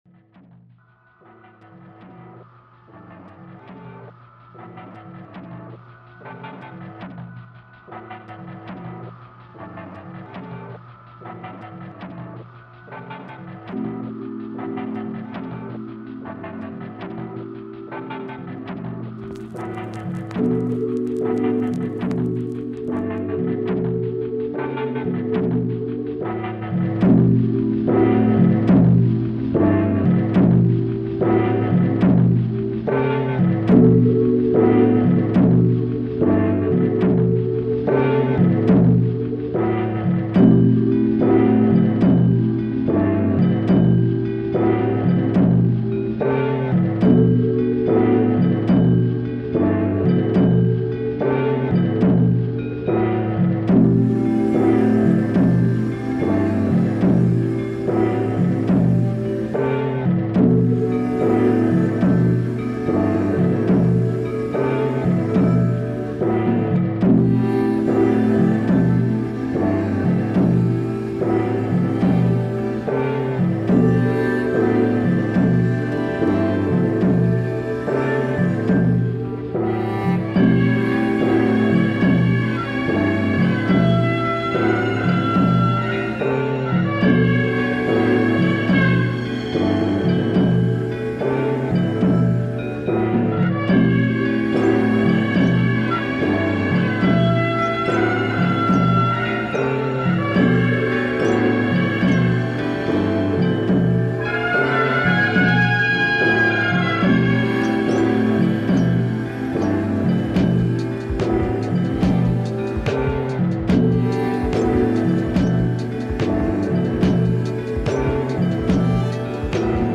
I chose this recording - an orchestral Korean recording from Seoul, 1972 - after initially earmarking another sound, but this one spoke to me with its uplifting, playful and mischievous spirit & felt right. Atmospherically, it evoked a busy market place or a ceremonial dance, putting me in mind of the benevolent chaos of the ‘hungry ghost’ concept - more of which later.
The instruments featured in the recording are: traditional Korean percussion - probably a Janggu - and two double-reeded wind instruments : the Hojok and the Hyang P’iri, both similar in tone to an oboe and known for their loud and powerful sound.